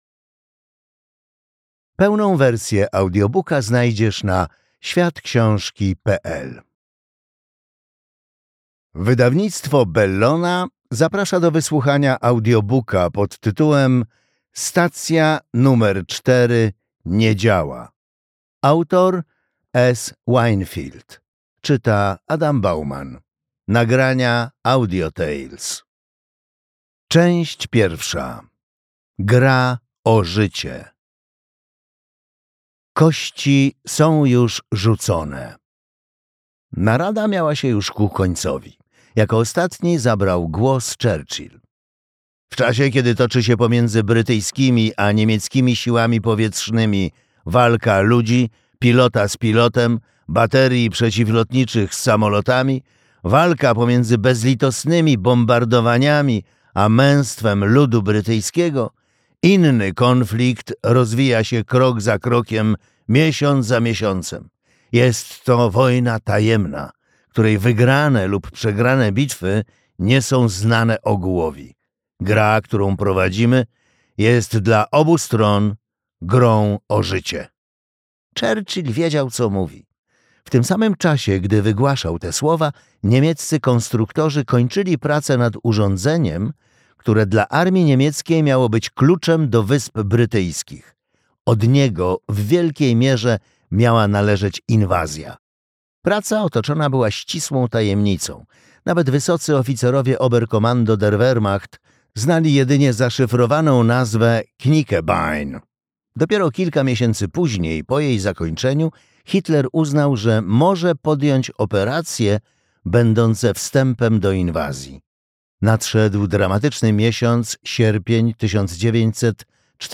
Stacja nr 4 nie działa - S. Winefield - audiobook